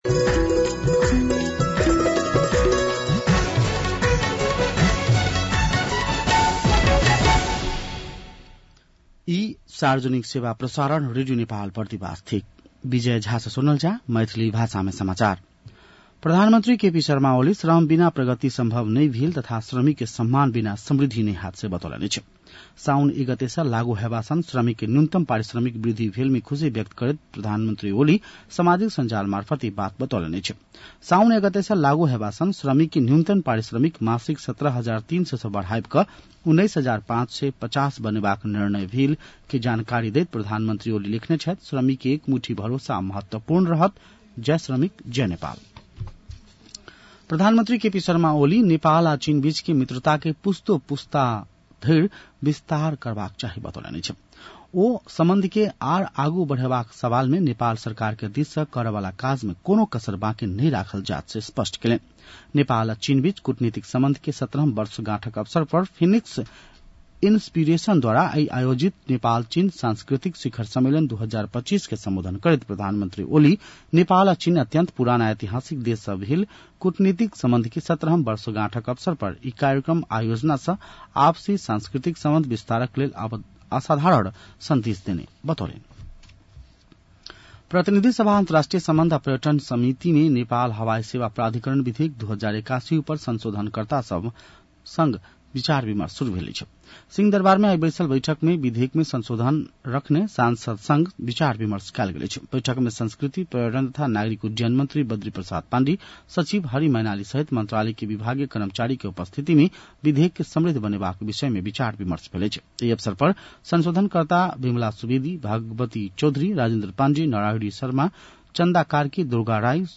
मैथिली भाषामा समाचार : २ साउन , २०८२